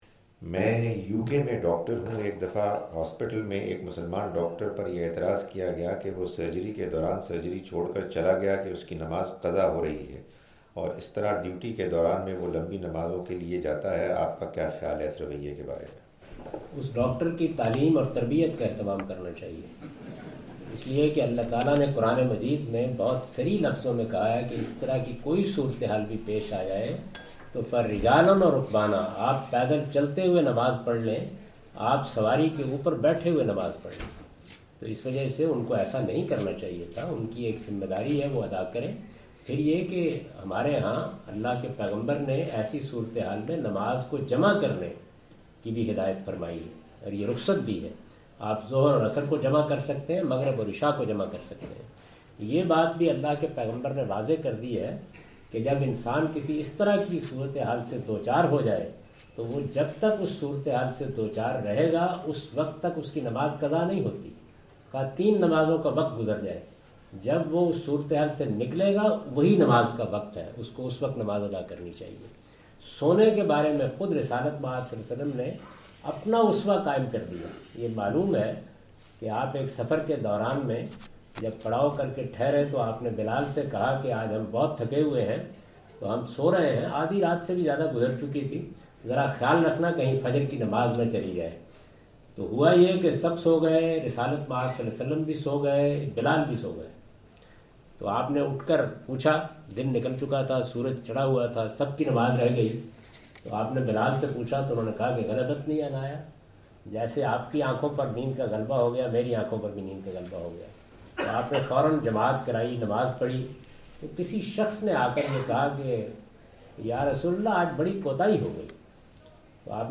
Javed Ahmad Ghamidi answer the question about "can doctors leave duties for Prayers(Salah)?" during his visit to Manchester UK in March 06, 2016.
جاوید احمد صاحب غامدی اپنے دورہ برطانیہ 2016 کے دوران مانچسٹر میں "کیا ڈاکٹر نماز کے لیے اپنی ڈیوٹی چھوڑ سکتا ہے؟" سے متعلق ایک سوال کا جواب دے رہے ہیں۔